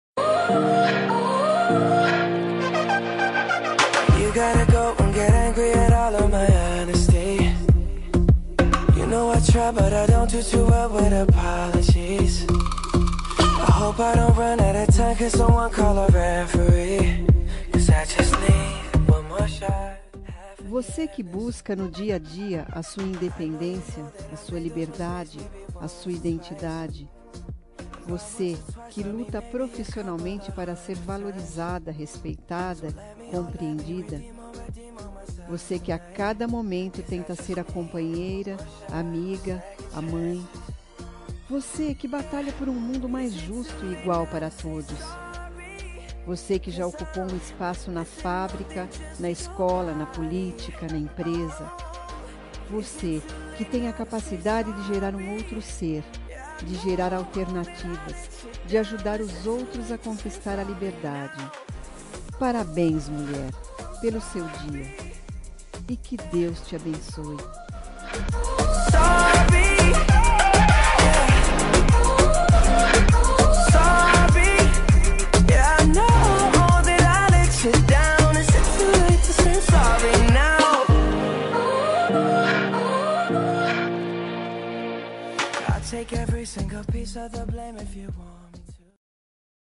Dia das Mulheres Neutra – Voz Feminina – Cód: 5291